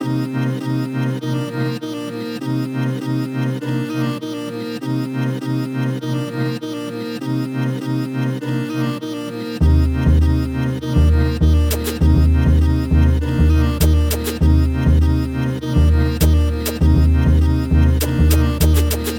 Each sample is meticulously crafted to help you achieve the same hard-hitting and soulful vibe that Divine is known for.
Gully-Loops-Chote-Melody-Loop-BPM-100-D-Maj.wav